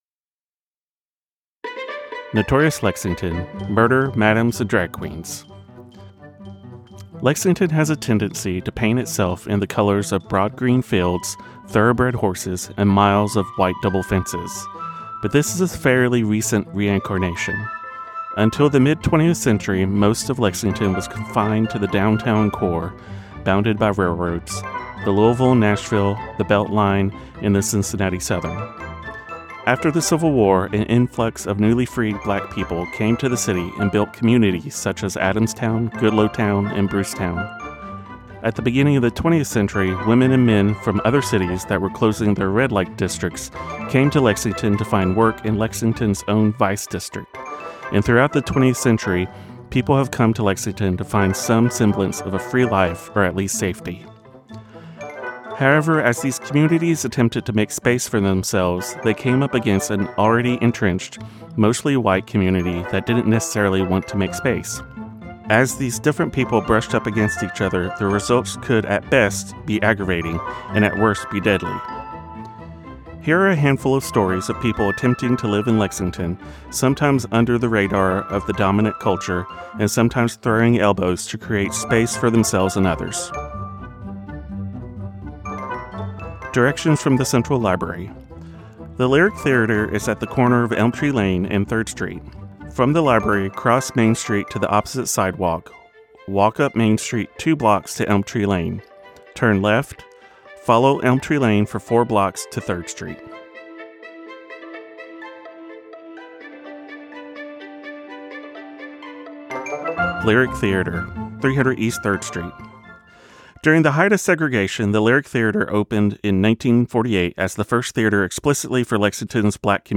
For the single MP3, music will play between the stops.